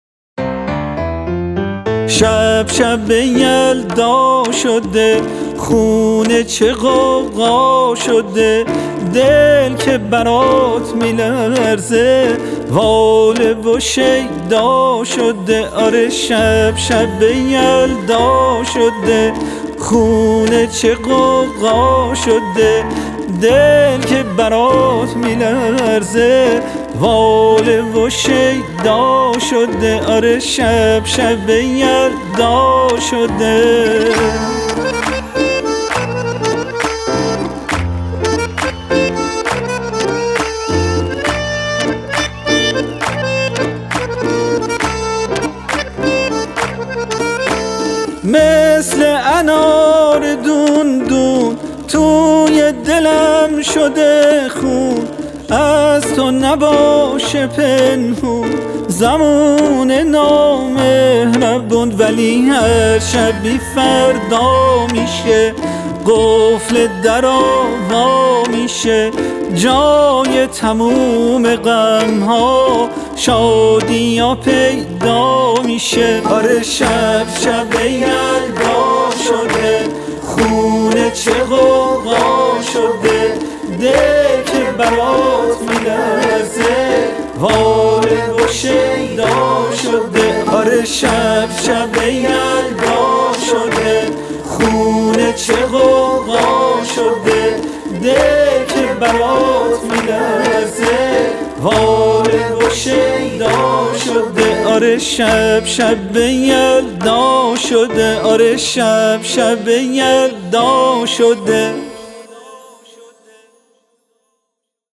آهنگ شاد مخصوص شب یلدا